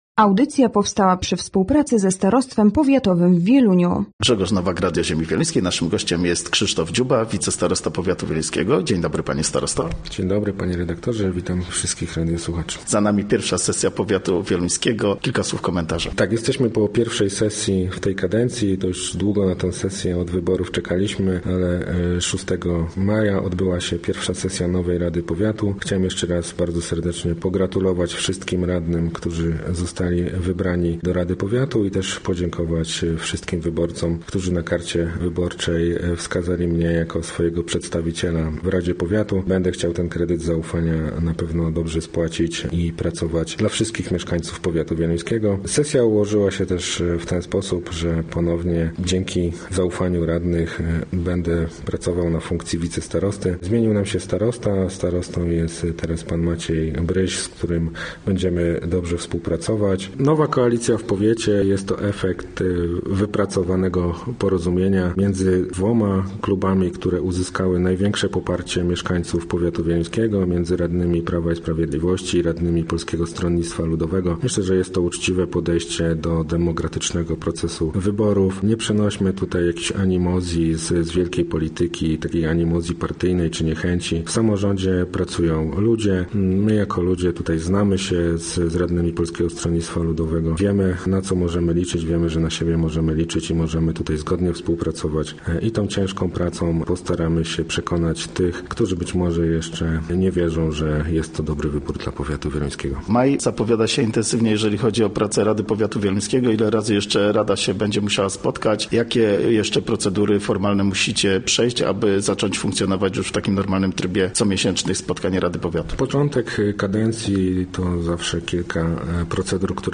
Gościem Radia ZW był Krzysztof Dziuba, wicestarosta powiatu wieluńskiego